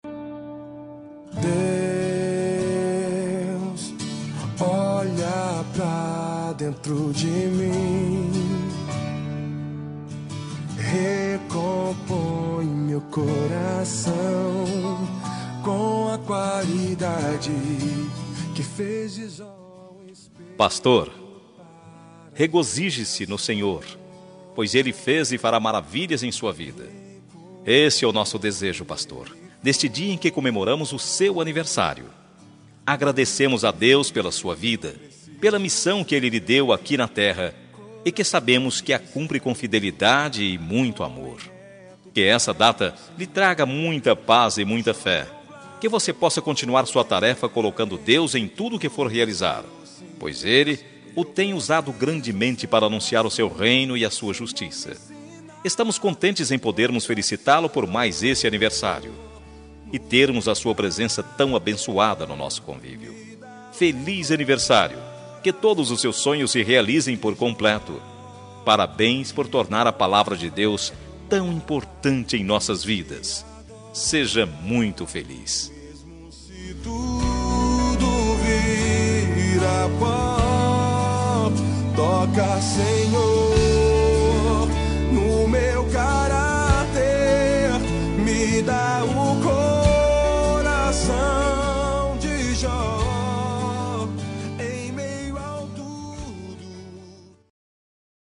Aniversário de Pastor – Voz Masculina – Cód: 5493